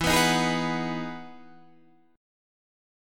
E Major 9th